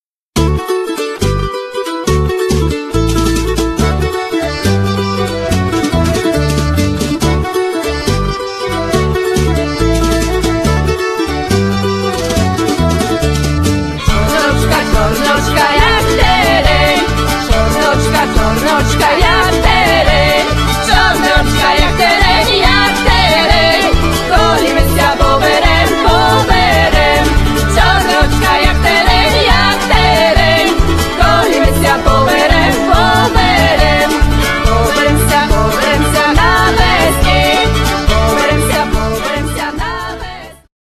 akordeon, sopiłki, fujara słowacka, tamburyn, ¶piew
basbałałaja, drumla, instrumenty perkusyjne, ¶piew
gitara, mandolina
skrzypce
zestaw perkusyjny